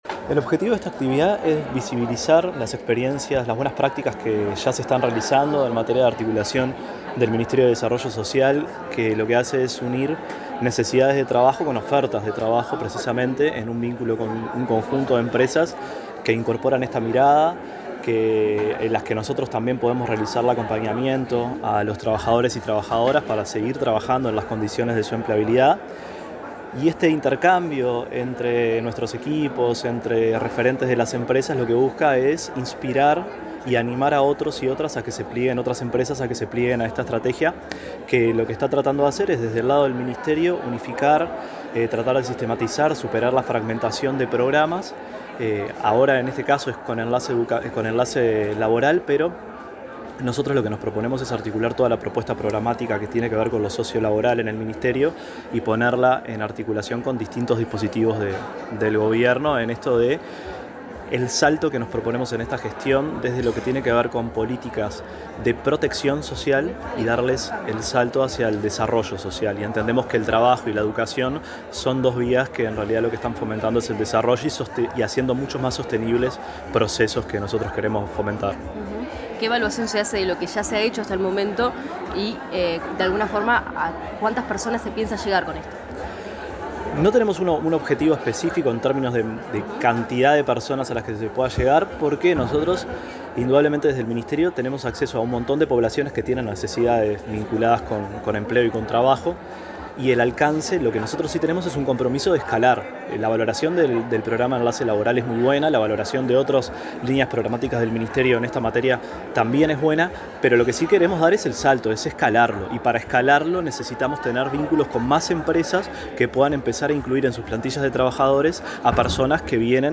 Declaraciones del director nacional de Desarrollo Social, Nicolás Lasa 13/08/2025 Compartir Facebook X Copiar enlace WhatsApp LinkedIn En el marco del conversatorio Buenas Prácticas de Inclusión Sociolaboral, el director nacional de Desarrollo Social, Nicolás Lasa, realizó declaraciones a la prensa.